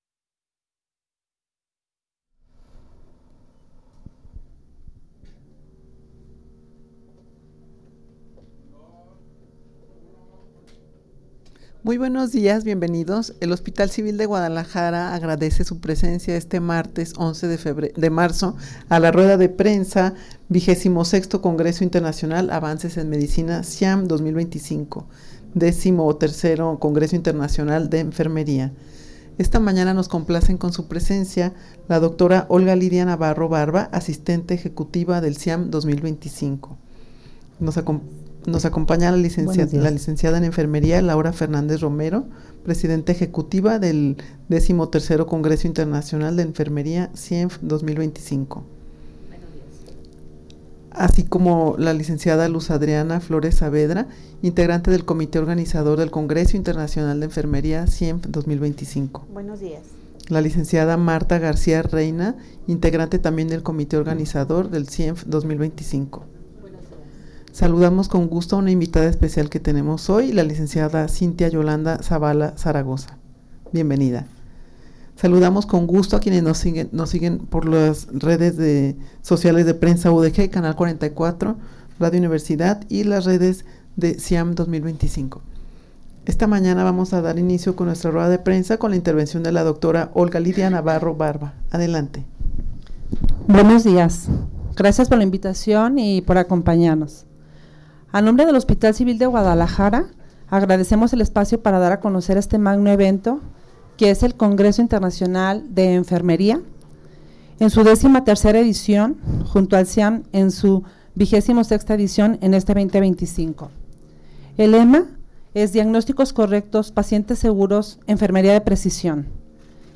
Audio de la Rueda de Prensa
rueda-de-prensa-para-anunciar-el-xii-congreso-internacional-de-enfermeria-en-el-marco-del-xxvi-ciam-2025.mp3